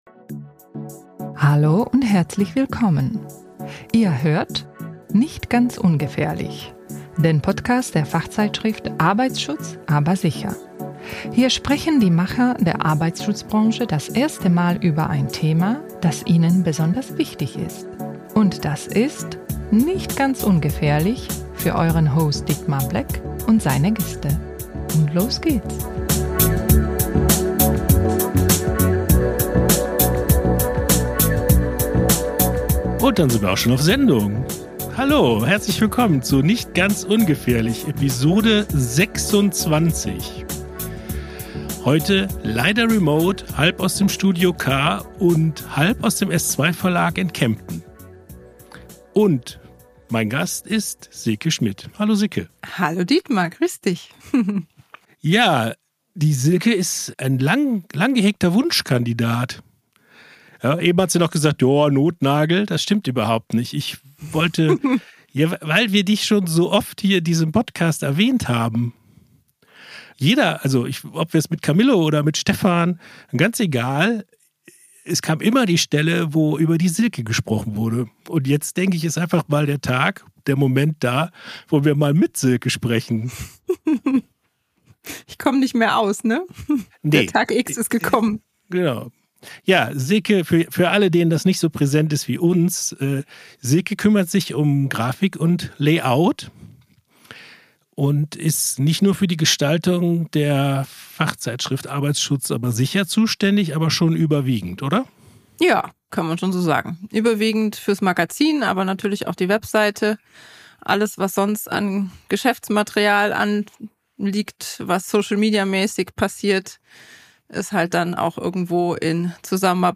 Gutgelaunt berichtet